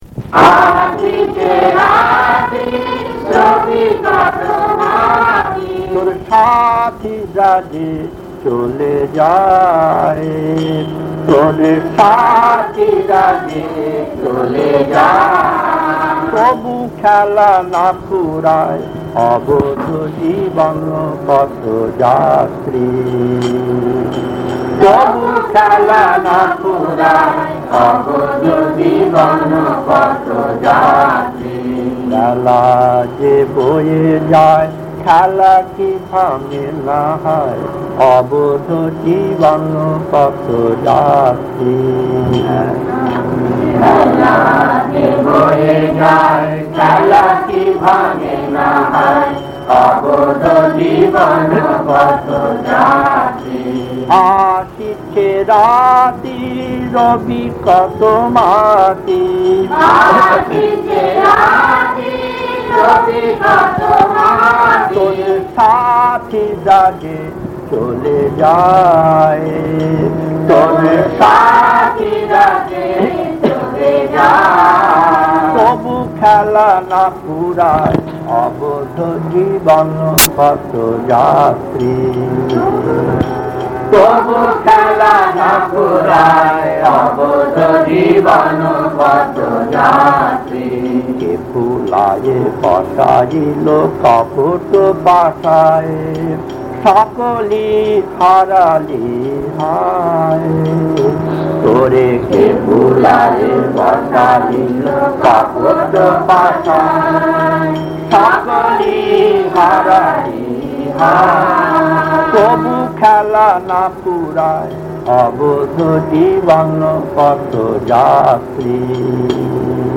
Kirtan A4-1 Puri December 1972 1.